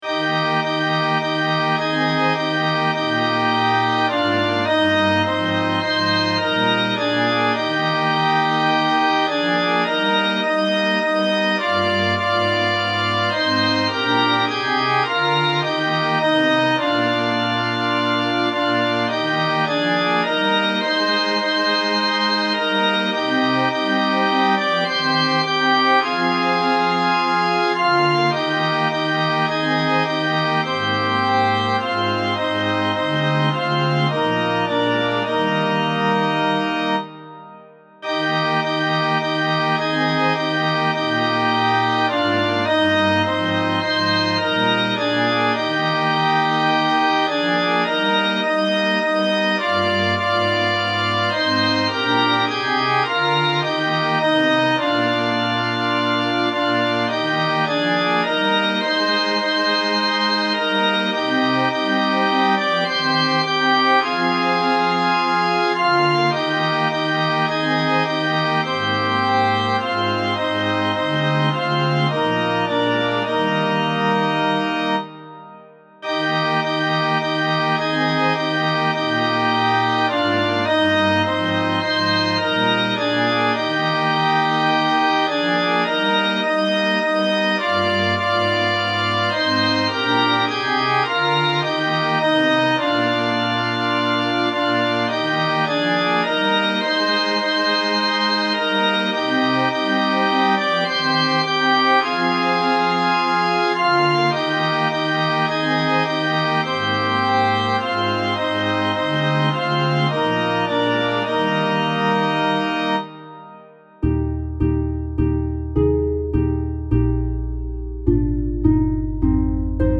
As has become my custom, I’m providing separate files for the treble clef and the bass clef. This allows me to assign a different instrument to each in GarageBand. I’ve also included a separate file for the trailing Amen.
If you have the piano script, you can play the full song on the macOS command line using: